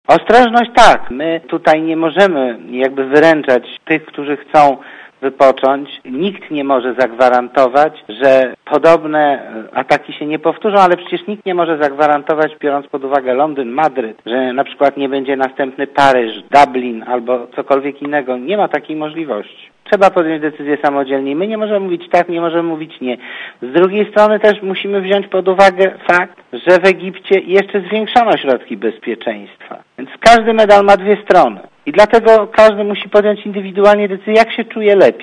Komentarz audio Oceń jakość naszego artykułu: Twoja opinia pozwala nam tworzyć lepsze treści.